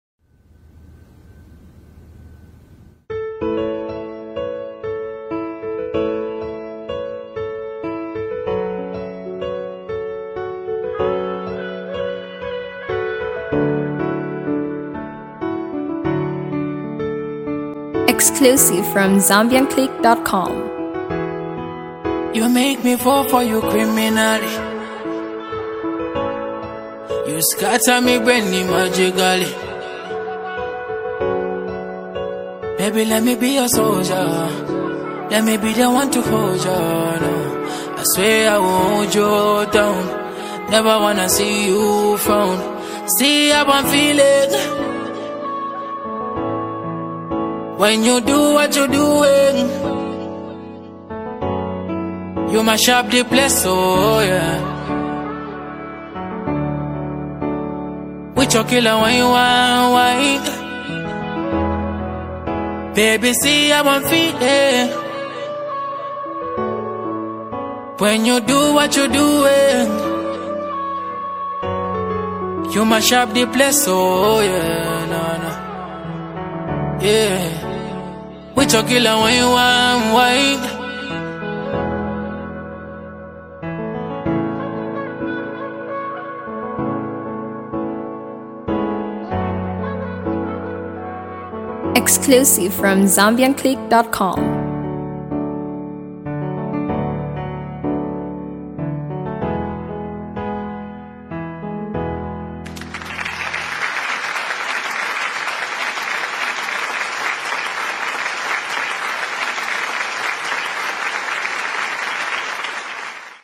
Zambian legendary and late Vocalist